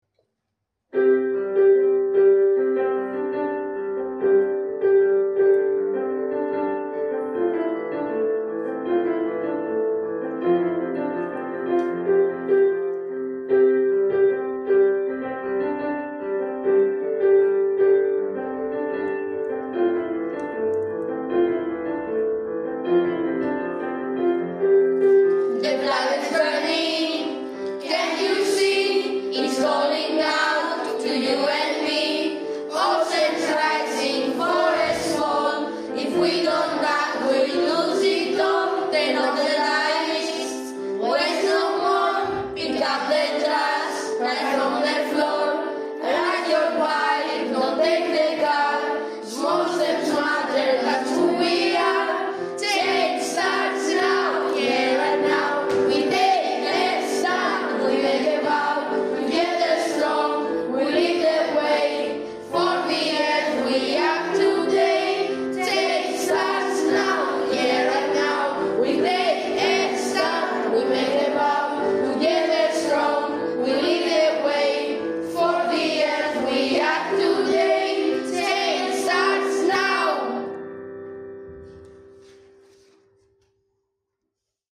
EHA projektilaulu salvestus õpilaste esituses